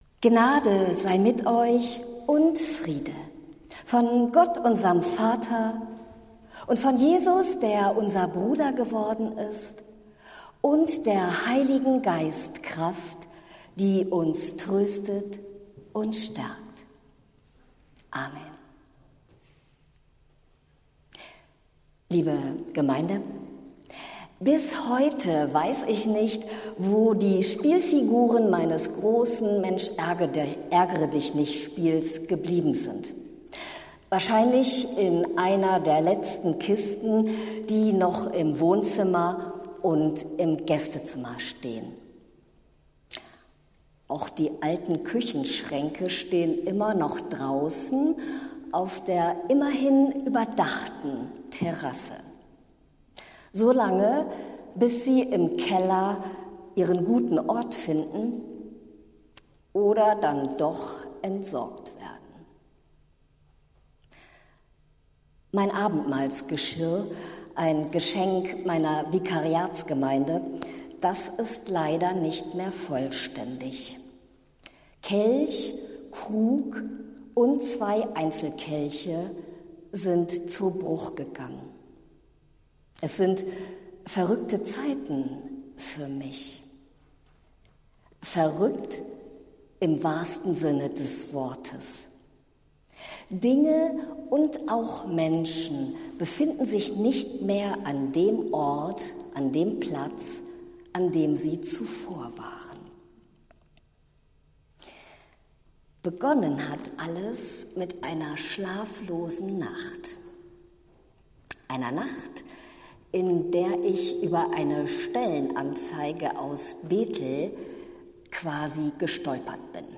Predigt des Gottesdienstes aus der Zionskirche vom Sonntag, den 31. August 2025